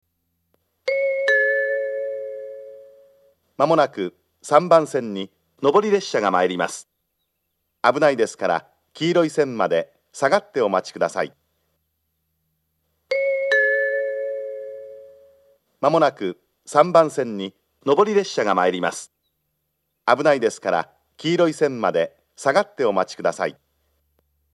matsuyamamachi-2bannsenn-sekkinn2.mp3